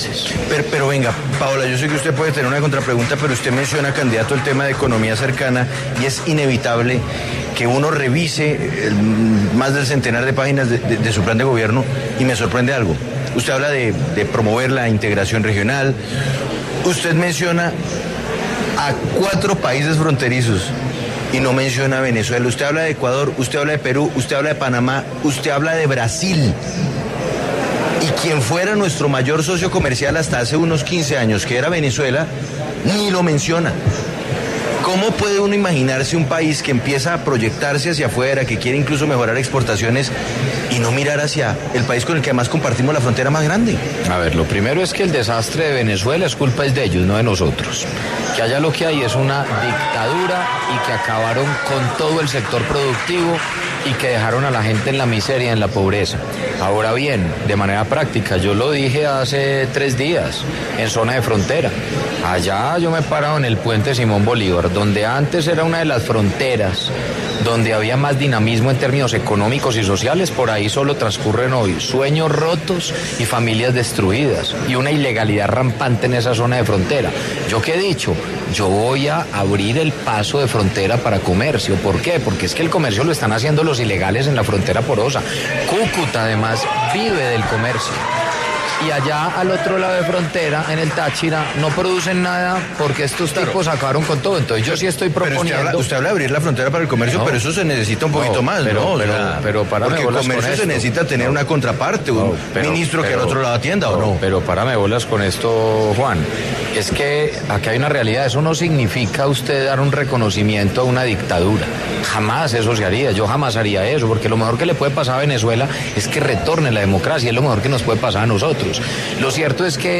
En diálogo con Sigue La W, el candidato presidencial Federico Gutiérrez estuvo contando su plan de gobierno y se refirió a qué pasará con las relaciones con Venezuela si llega a ser presidente.